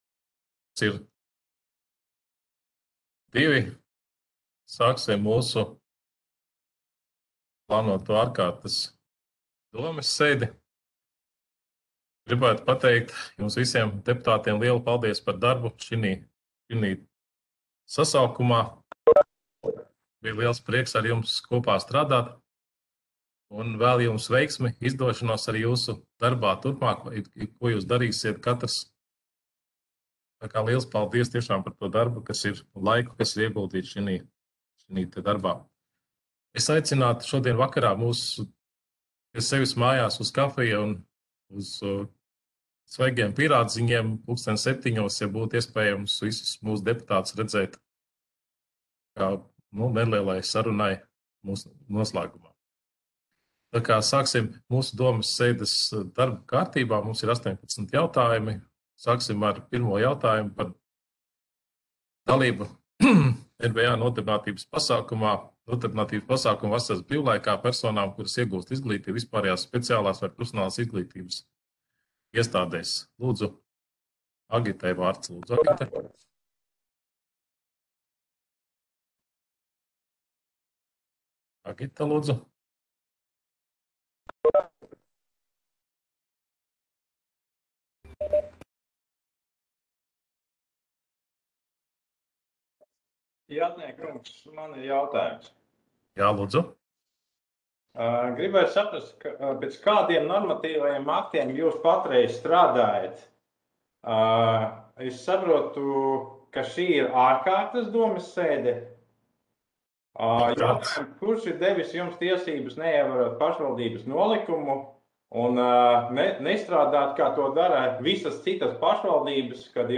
Audioieraksts - 2021.gada 17.jūnija domes ārkārtas sēde